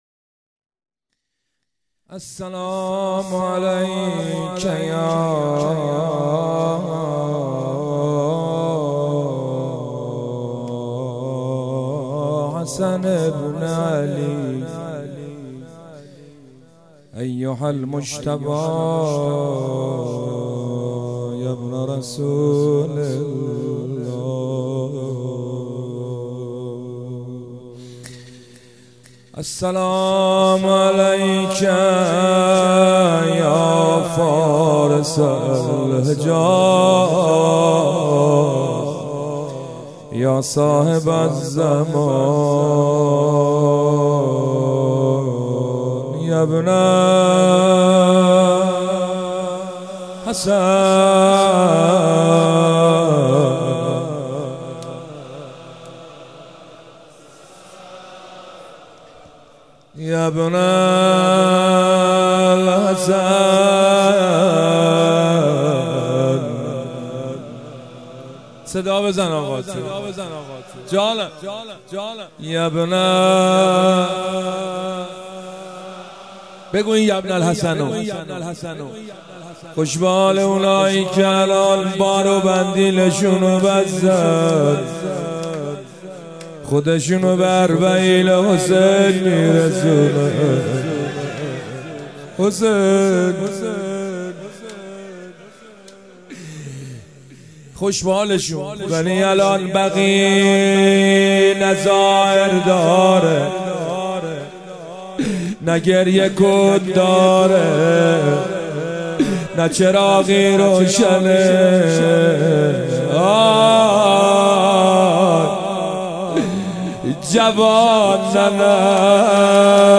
دانلود نوحه شهادت امام حسن مجتبی